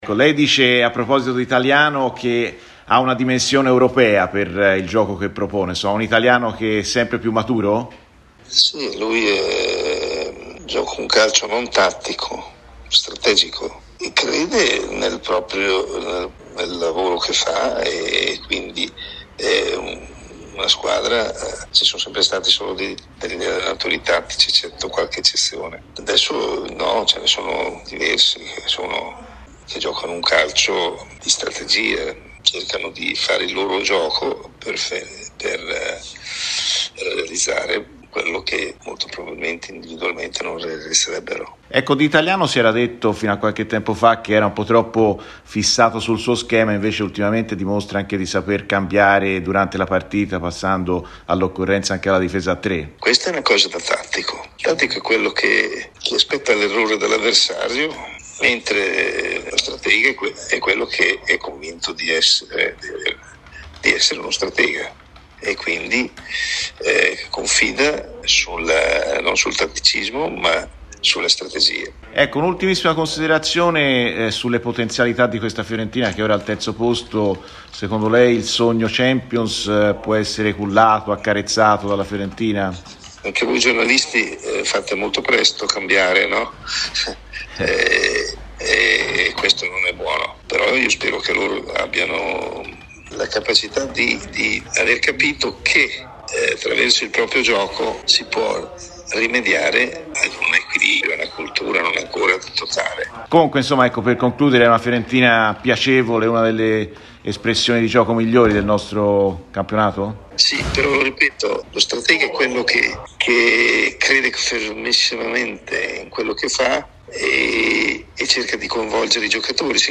Intervistato da Radio Firenze Viola l'ex allenatore del grande Milan degl' olandesi e della Nazionale italiana Arrigo Sacchi ha parlato dell'ottimo avvio di stagione della Fiorentina e in particolare delle qualità dell'allenatore viola Vincenzo Italiano: "Italiano crede nel lavoro che fa.